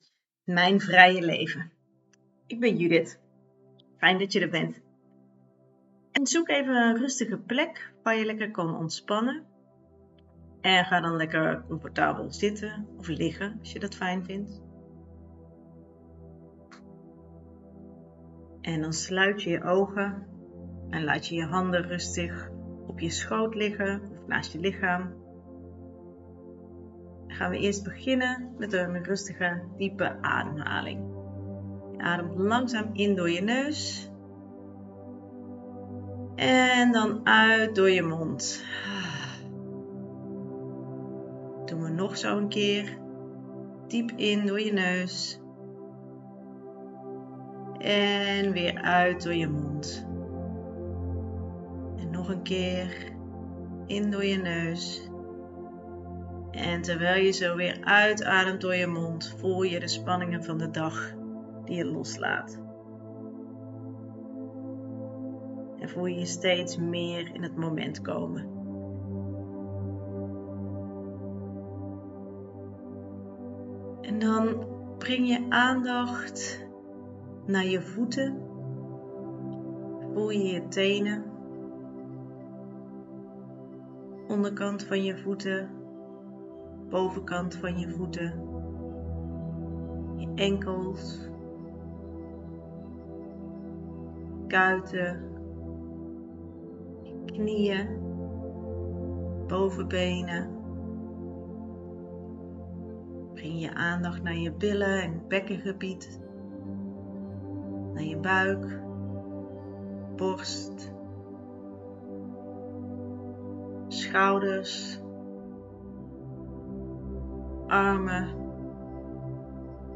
Visualisatie Mijn vrije leven